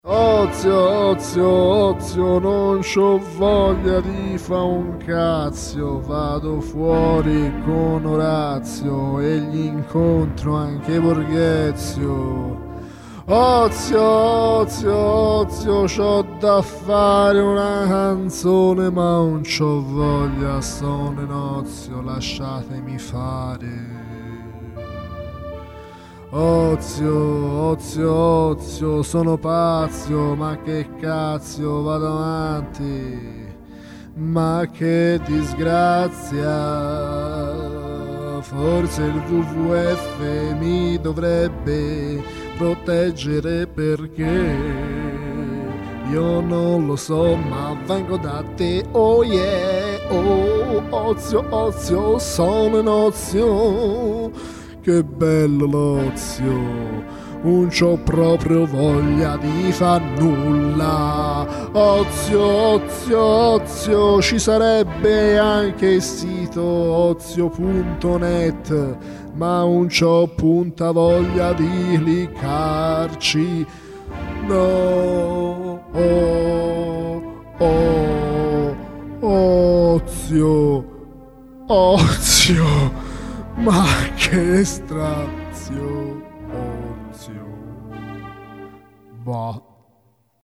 Non avevo punta voglia di fare una canzone sull'ozio, e allora l'ho totalmente improvvisata.